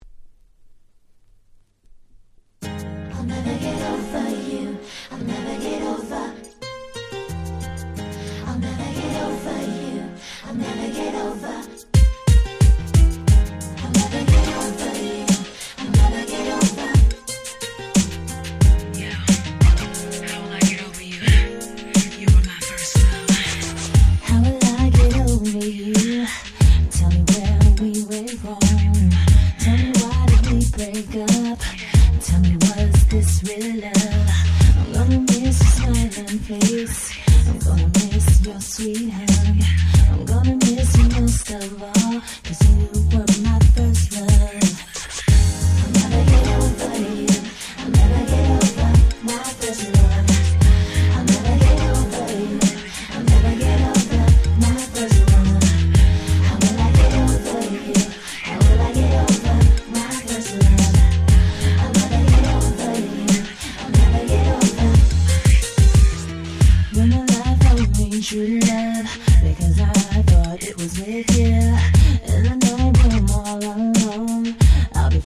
03' Nice R&B !!